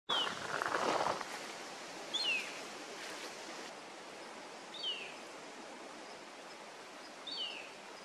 Chopí (Gnorimopsar chopi)
Nombre en inglés: Chopi Blackbird
Fase de la vida: Adulto
Localidad o área protegida: Parque Nacional El Palmar
Condición: Silvestre
Certeza: Vocalización Grabada